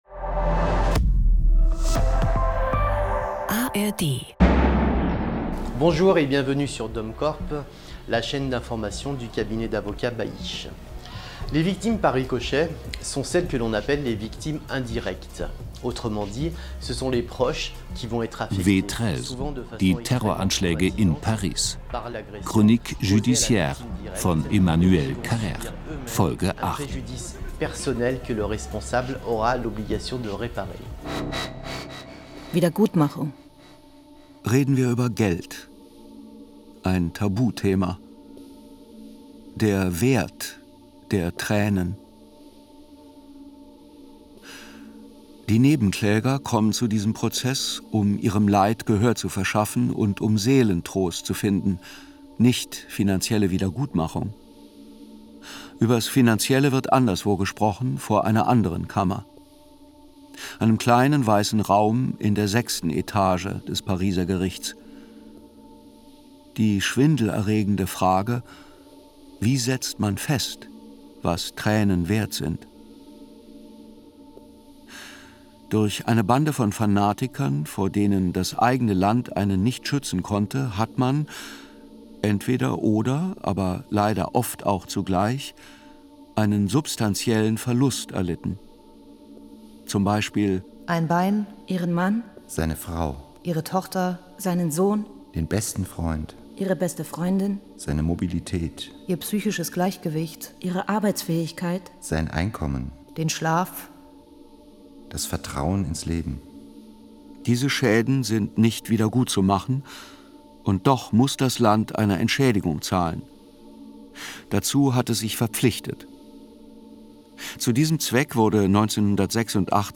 Ausgewählte Hörspiele von Krimis über Radiokunst bis zu großen Literaturhörspielen aus dem SWR2 Programm.